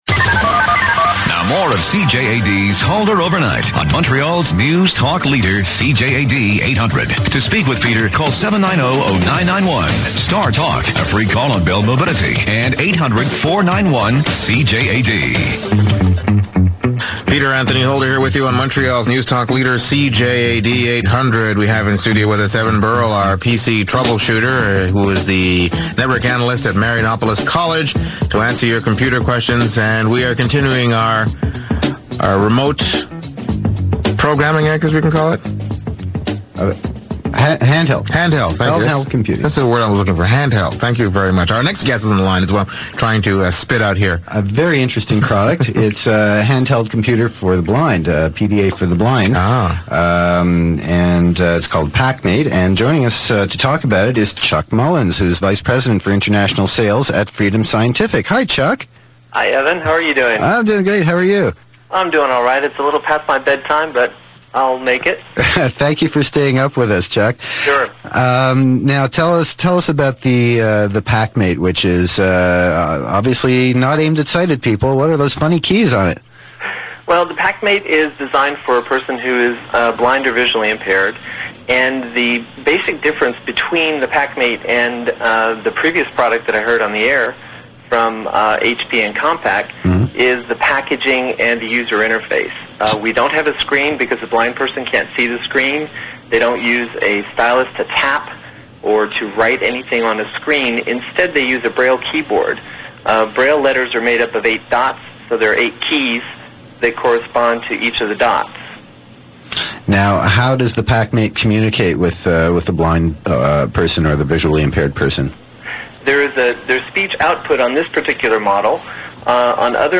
pacmate_radio_segment.mp3